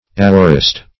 Aorist \A"o*rist\ ([=a]"[-o]*r[i^]st), n. [Gr.